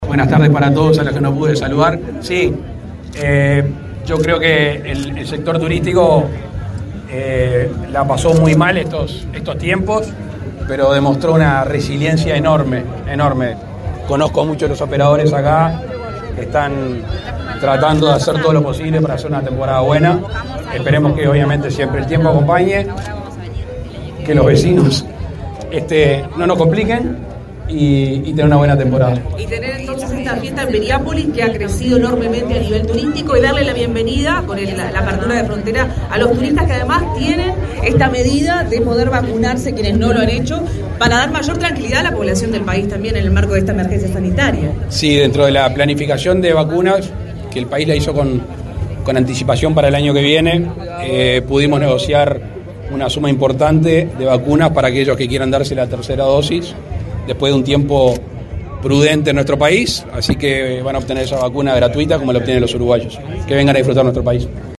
Palabras del presidente Luis Lacalle Pou
El presidente Luis Lacalle Pou participó este sábado 4 en la 22.ª edición de la Fiesta de la Paella Gigante en Piriápolis, Maldonado.